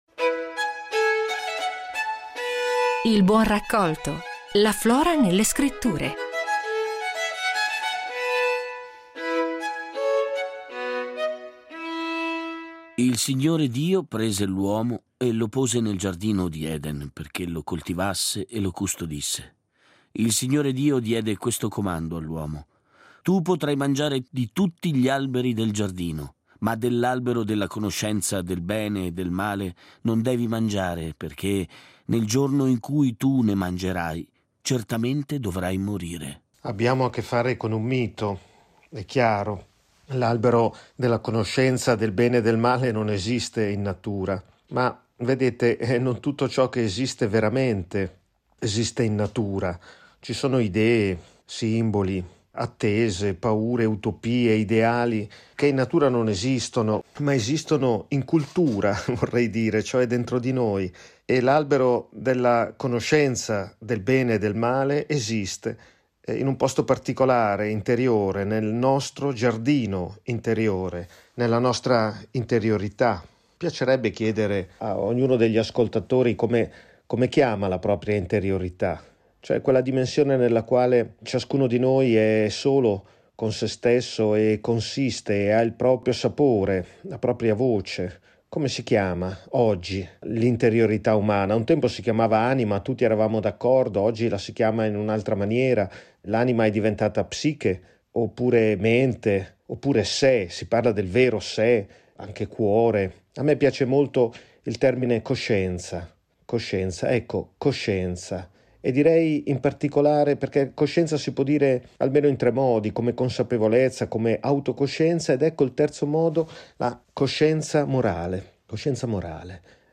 La riflessione di Vito Mancuso